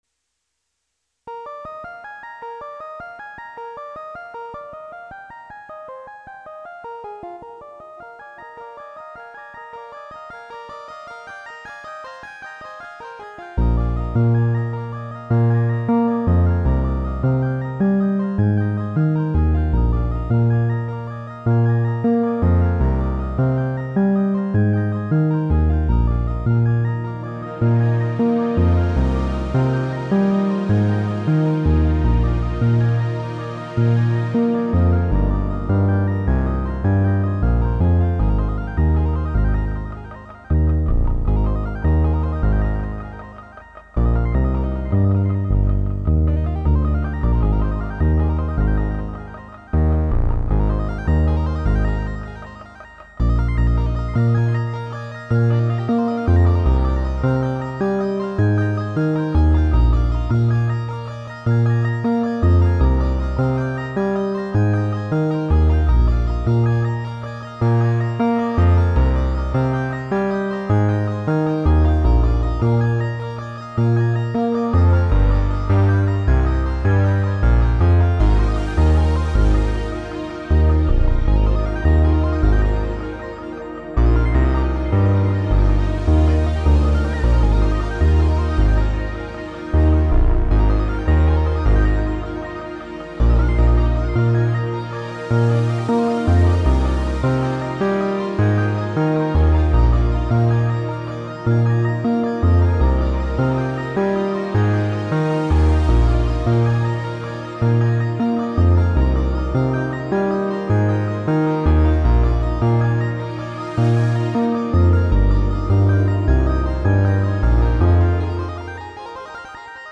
rock and/or roll
Written & performed on the Roland JD-XA